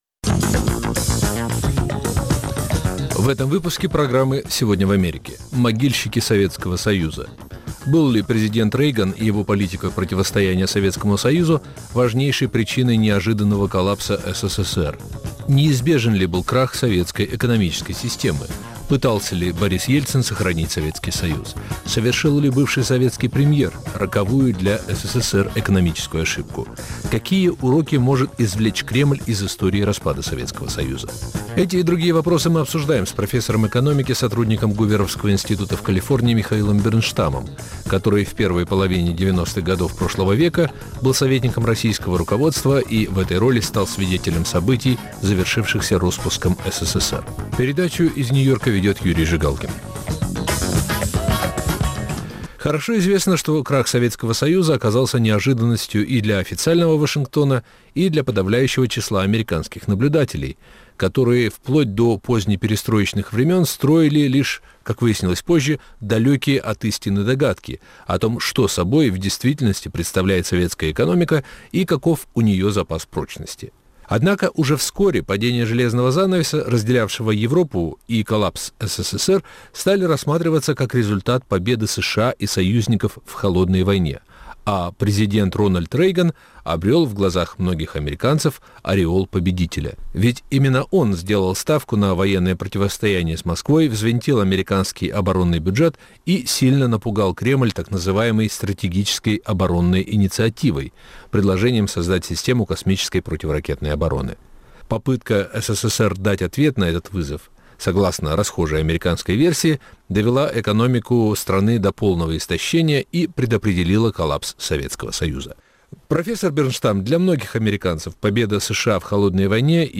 Эти вопросы мы обсуждаем с профессором экономики